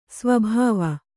♪ sva bhāva